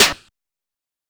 THE NAIL TO THE CROSS SNARE.wav